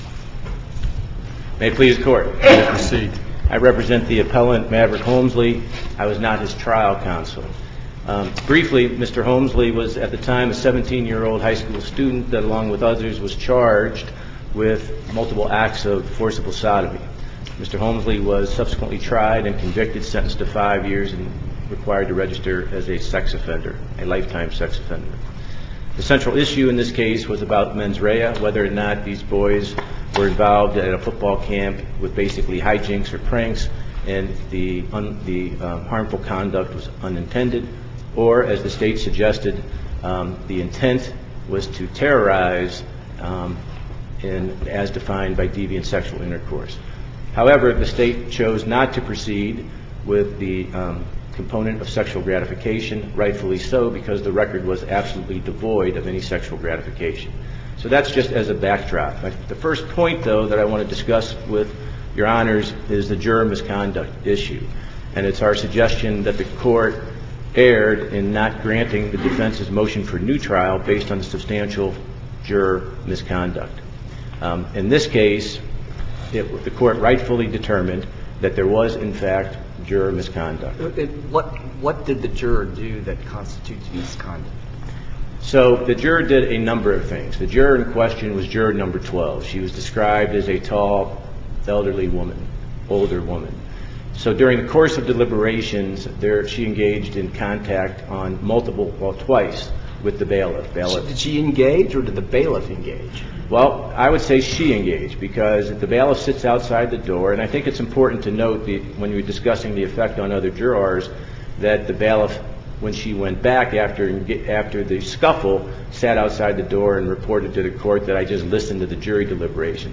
MP3 audio file of arguments in SC96639